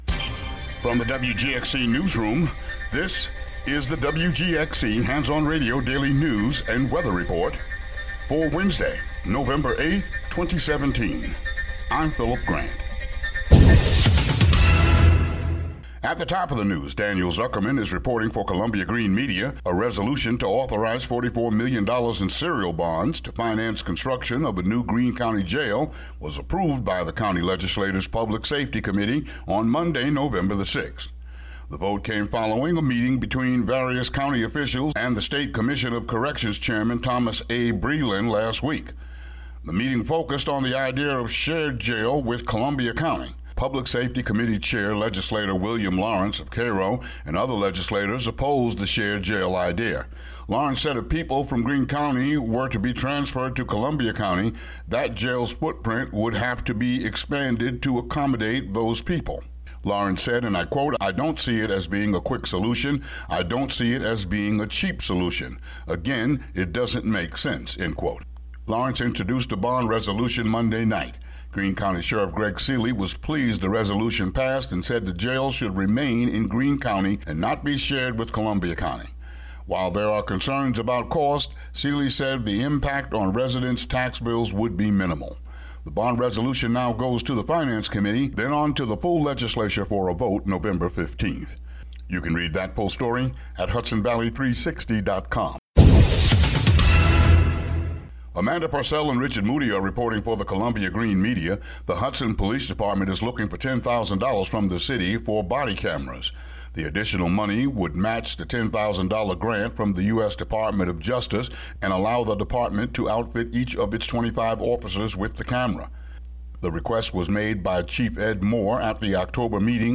WGXC daily headlines for Nov. 8, 2017.